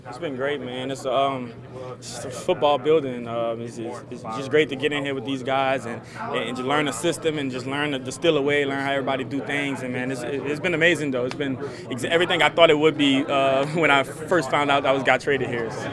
Jackson met with the media following Thursday’s workout and said he’s fitting in well with the Steelers.